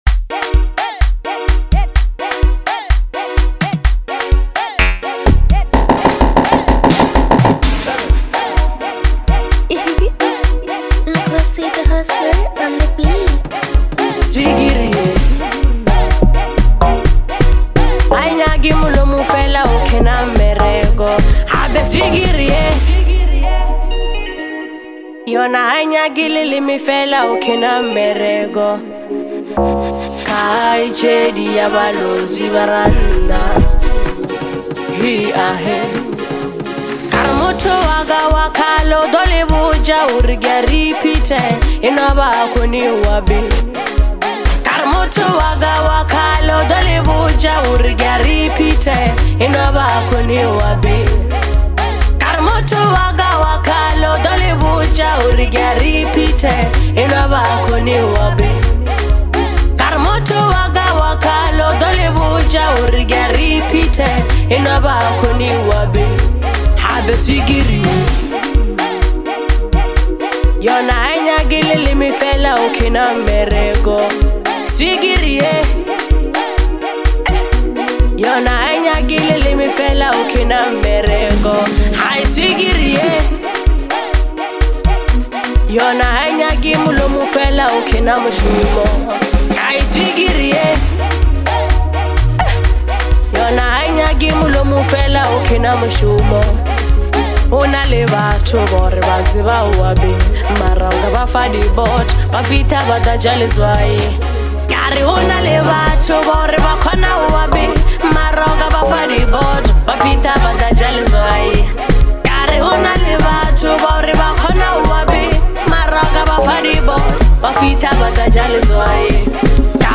vibrant rhythms and soulful melodies